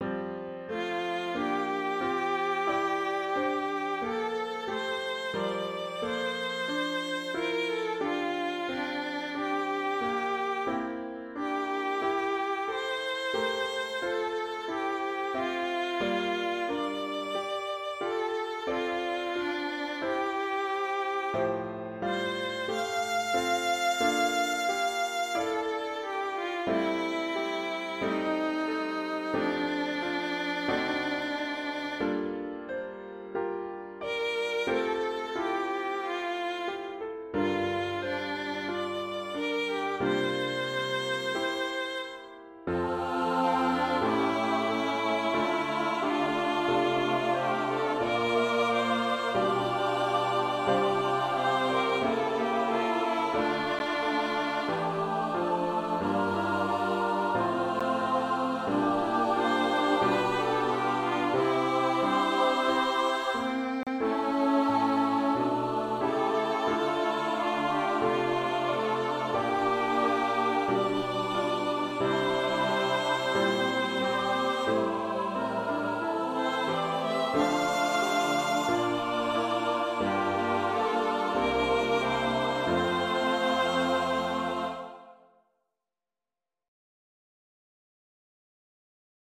einen vierstimmigen Satz